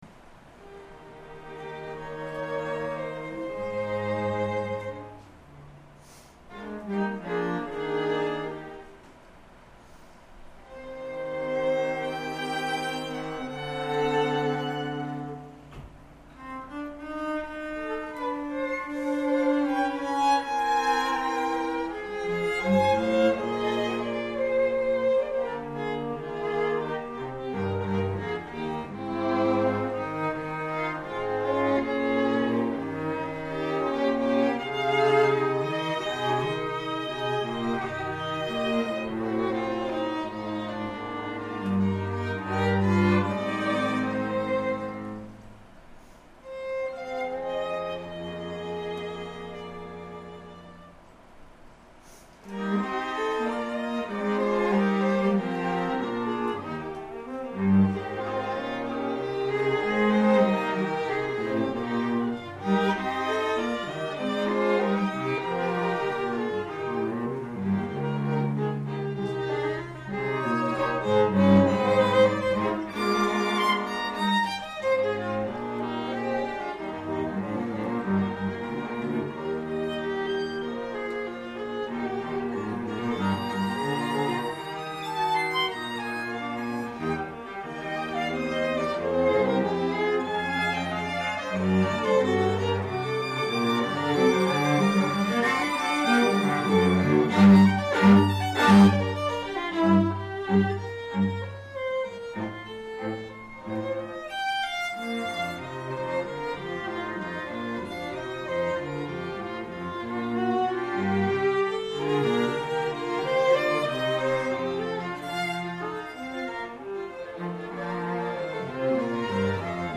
2:00 PM on July 28, 2012, South Bay Church
Adagio non troppo - Allegro non tardante